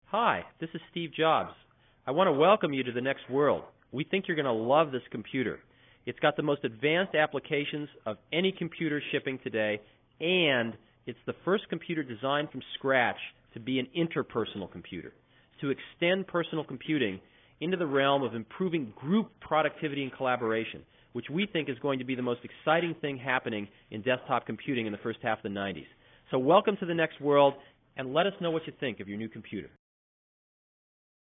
Listen to Steve Jobs speak to every NeXT user, via the first multimedia email message (as captured by AppStorey using original vintage NeXT Computers):
[Steve Jobs speaks via NeXTMail Lip-Service in 1988]
stevejobswelcometothenextworld.mp3